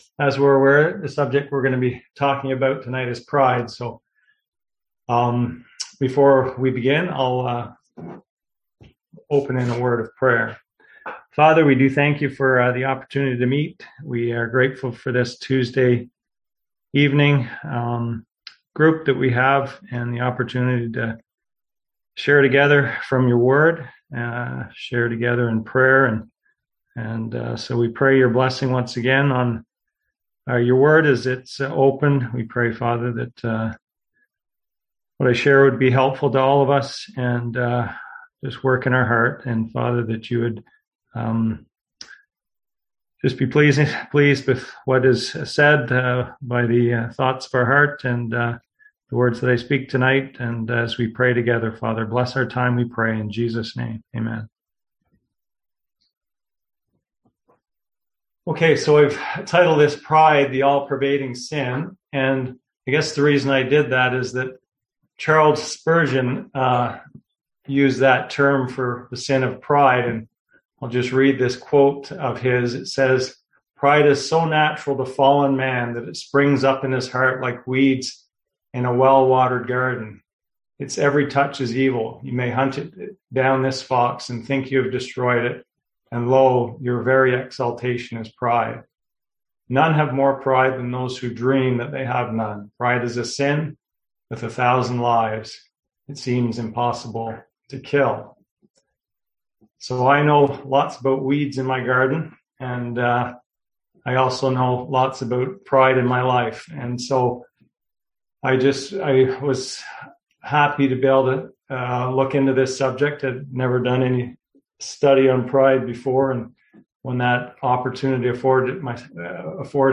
Series: Wisdom Passage: Proverbs 3:5-7; Proverbs 6:16-19; Proverbs 8:13 Service Type: Seminar